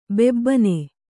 ♪ bebbane